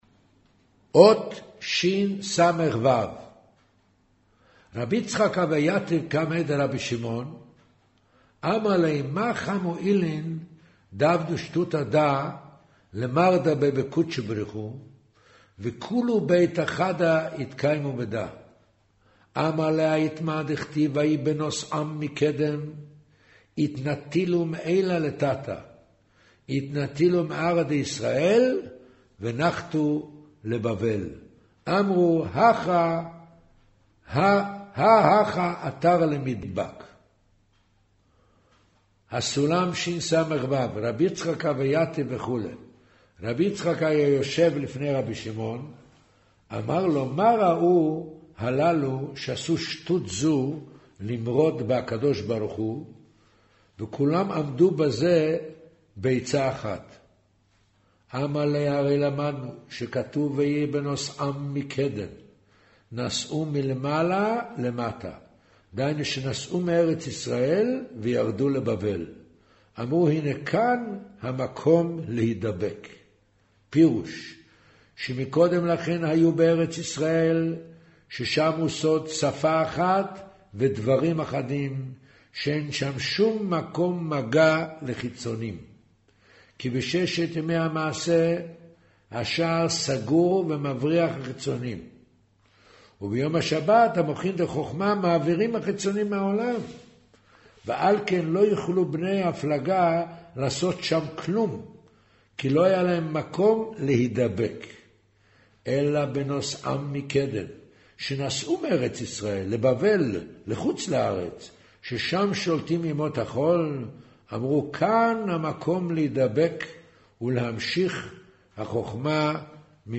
אודיו - קריינות זהר